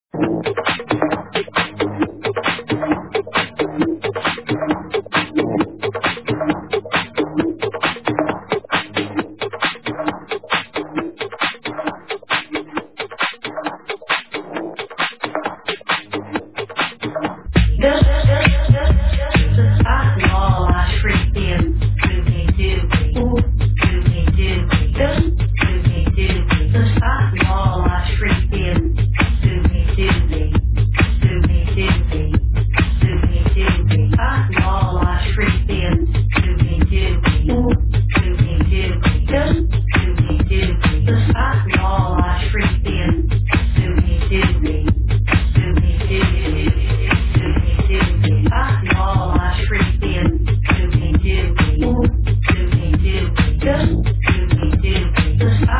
Techno track ID1